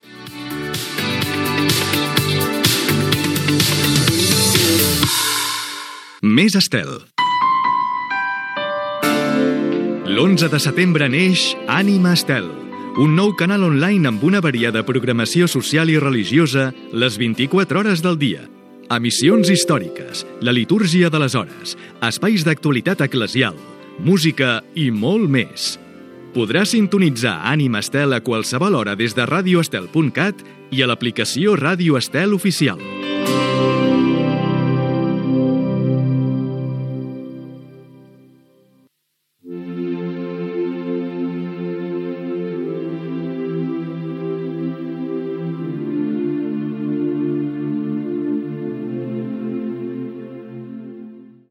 Música, identificació del canal, promoció del naixement d'"Ànima Estel", tema musical